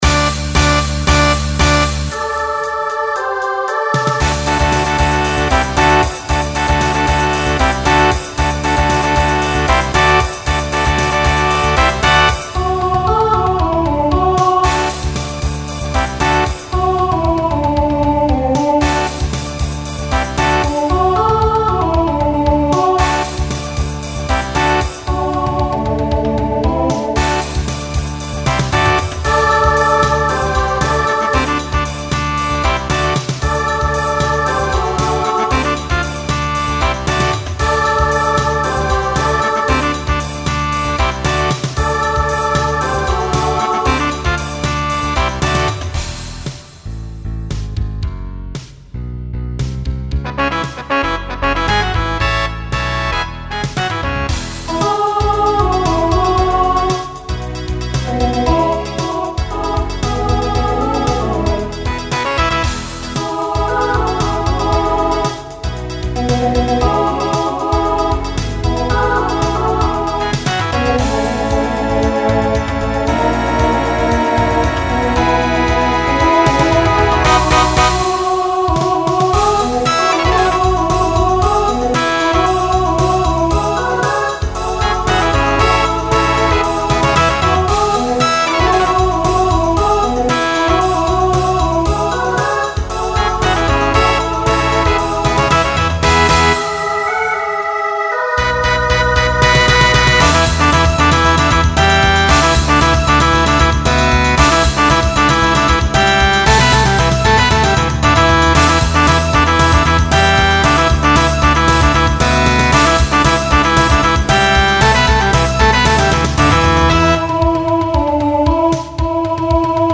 Story/Theme Voicing SSA